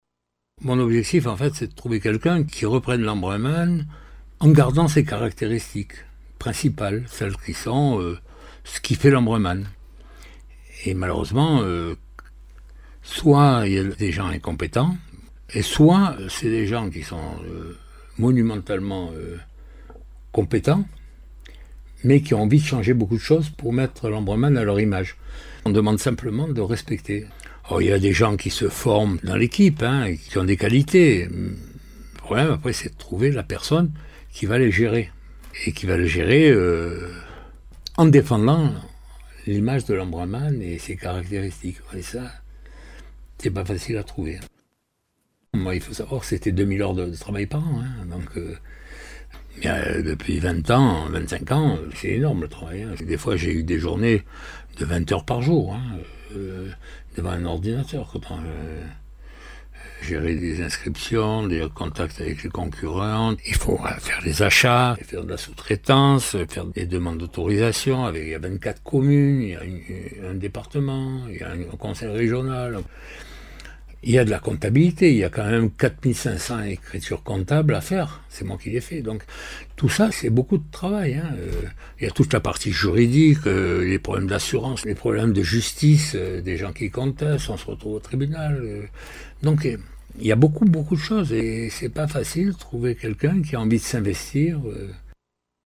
Interviews
• Après la course :